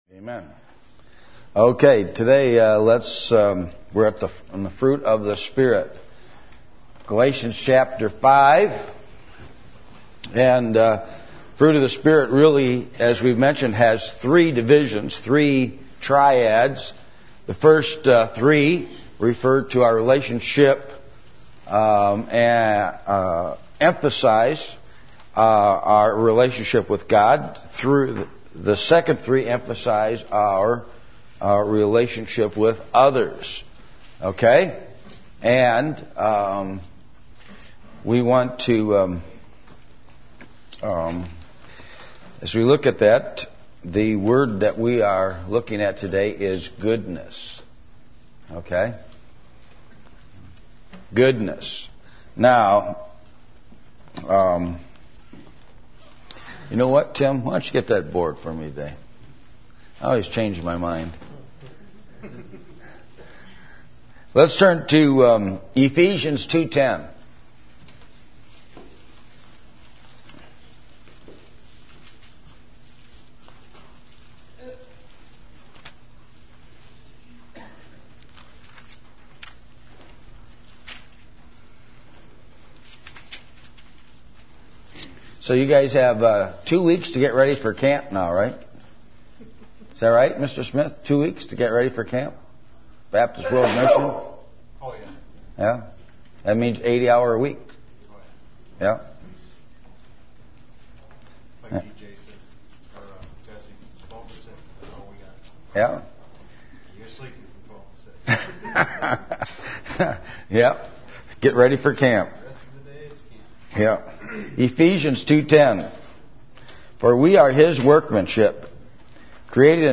Service Type: Adult Sunday School